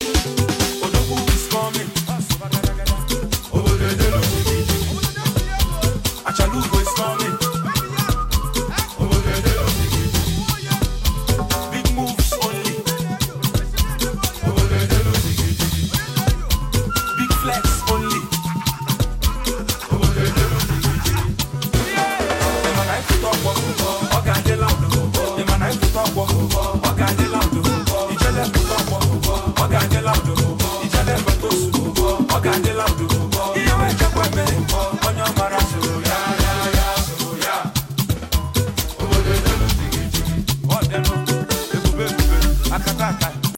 the award-winning Nigerian singer and songwriter